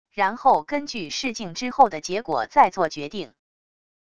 然后根据试镜之后的结果再做决定wav音频生成系统WAV Audio Player